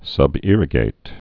(sŭb-îrĭ-gāt)